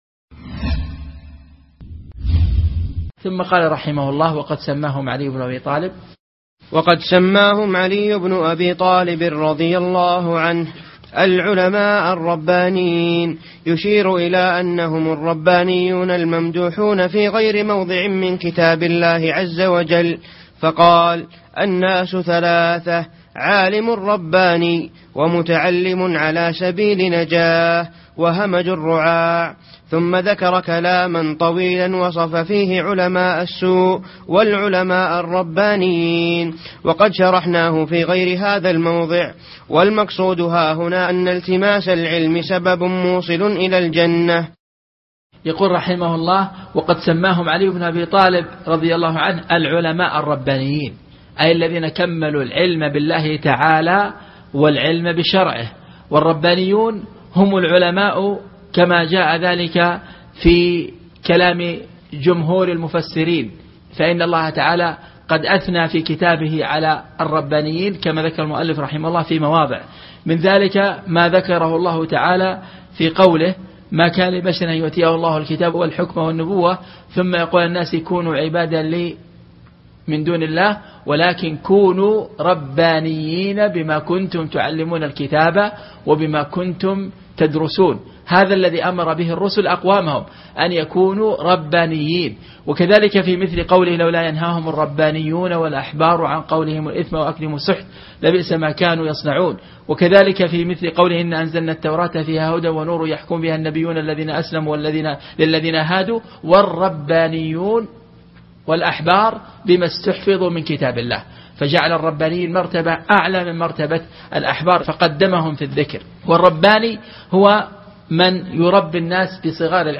الدرس( 7)العلماء الربانييون- ورثة الأنبياء